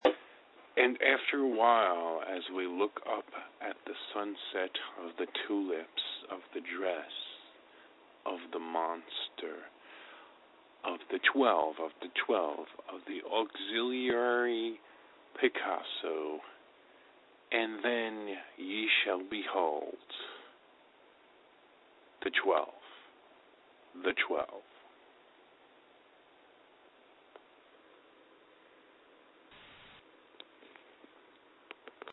Guitars
Drums
Keyboard